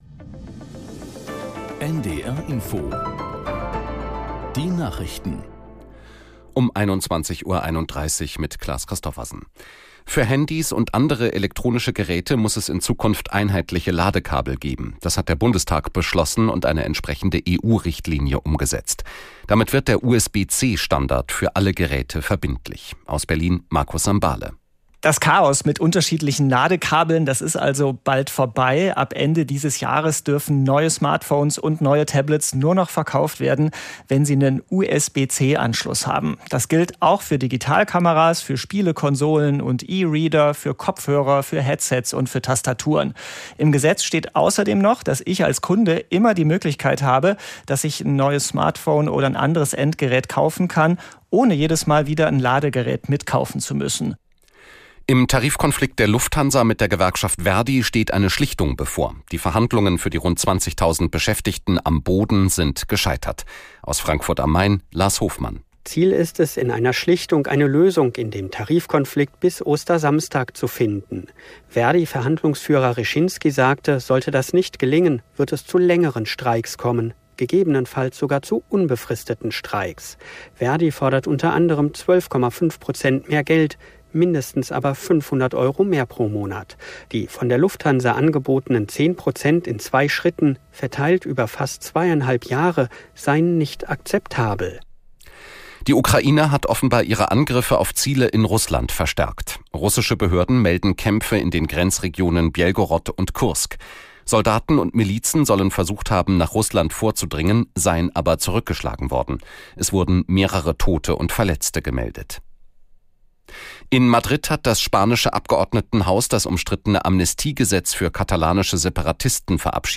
Nachrichten - 14.03.2024